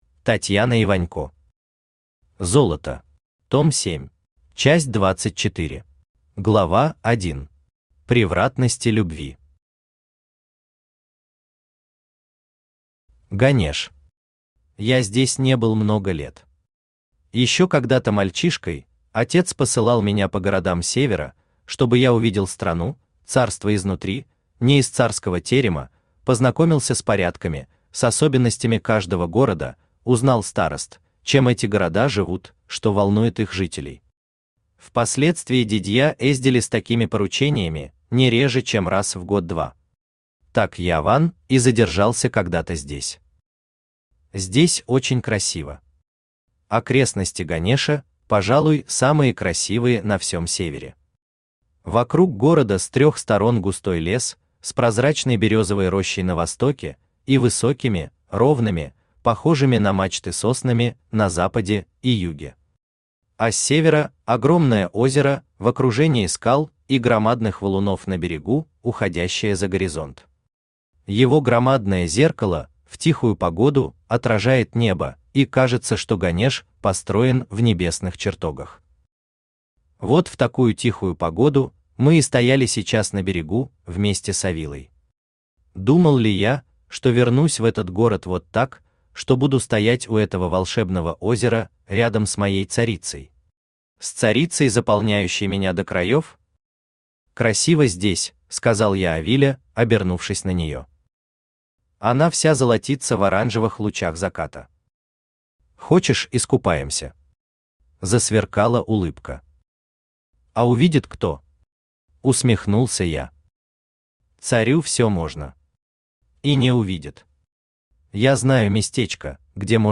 Аудиокнига Золото. Том 7 | Библиотека аудиокниг
Том 7 Автор Татьяна Вячеславовна Иванько Читает аудиокнигу Авточтец ЛитРес.